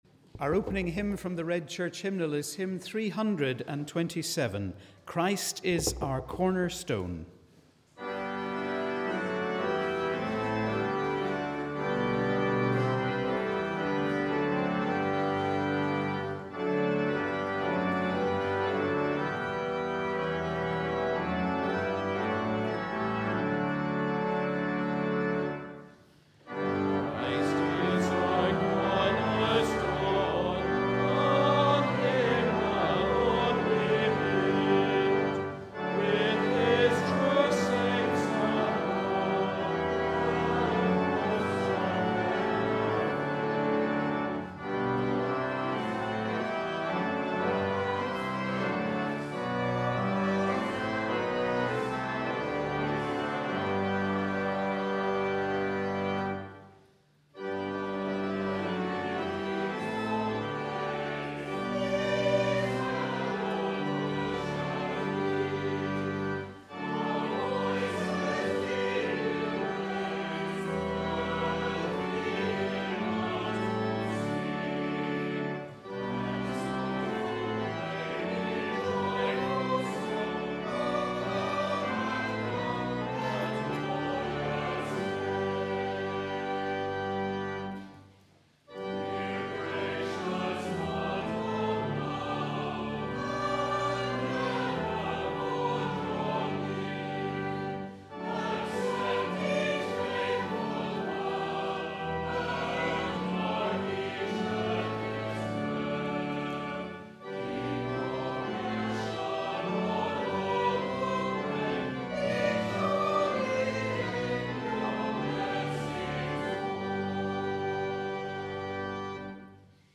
Wherever you are, we welcome you to our service of Morning Prayer on the 2nd Sunday after the Epiphany.